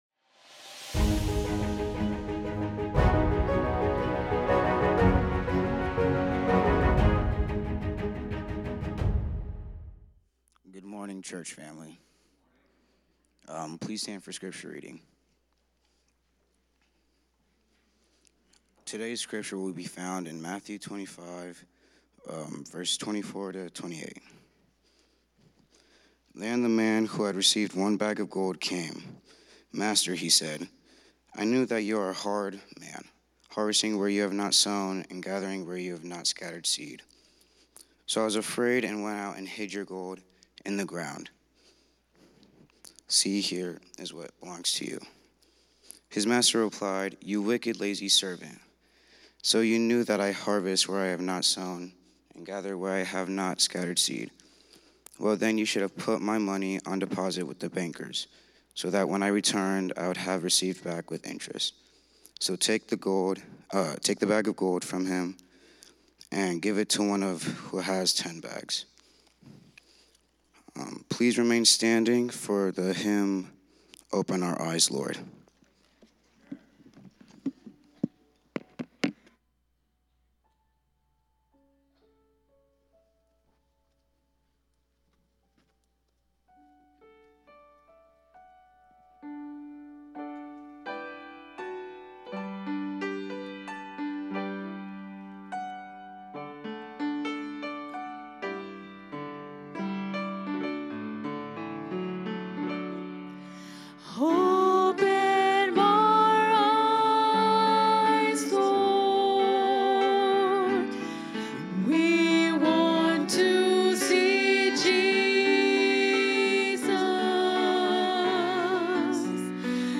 From Series: "Central Sermons"